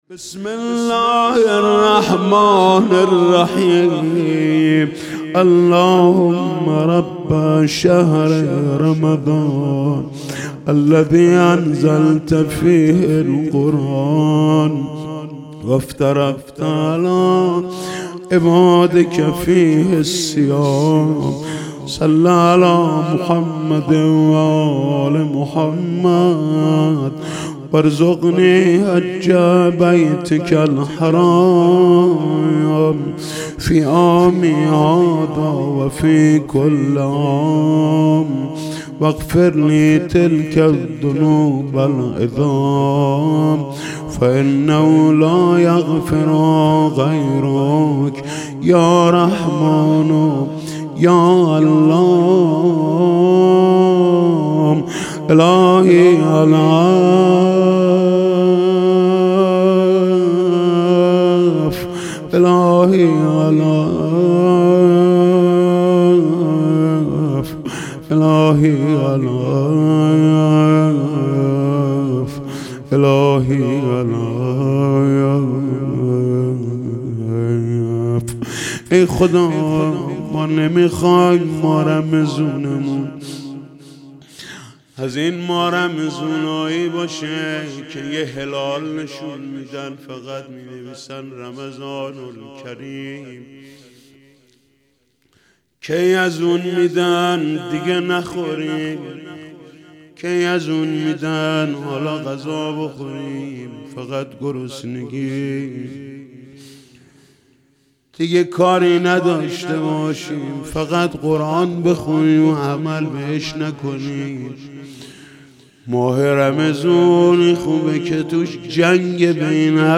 شب یازدهم رمضان 99 - قرائت دعای ابوحمزه ثمالی